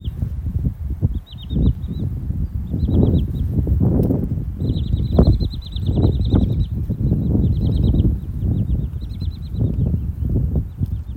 Putni -> Bridējputni ->
Purva tilbīte, Tringa glareola
Administratīvā teritorijaAlūksnes novads